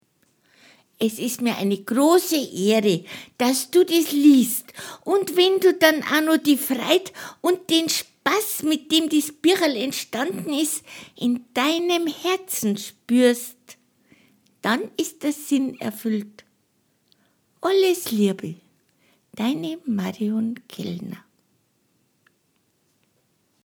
Boarische Verserl zu traumhaften Bildern
Gelesen von der Autorin.